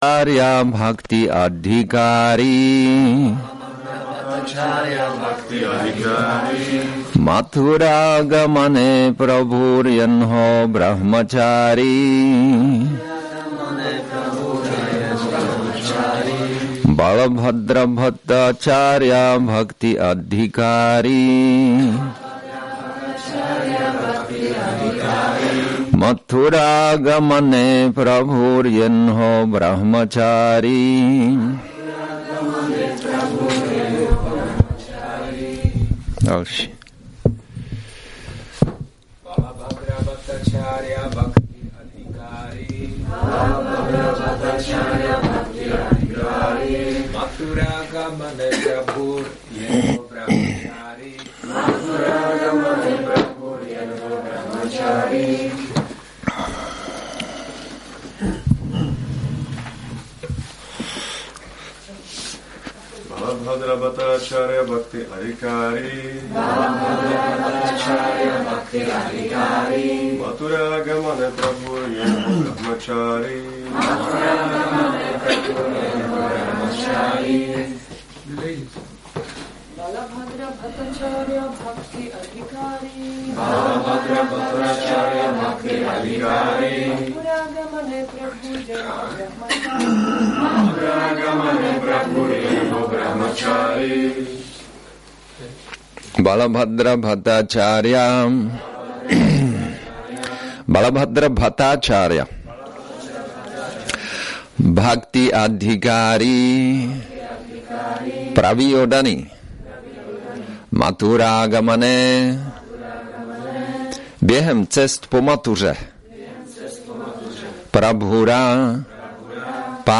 Přednáška CC-ADI-10.146